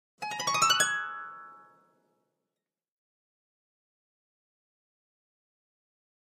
Harp, High Strings 7th Chords, Short Ascending Gliss, Type 4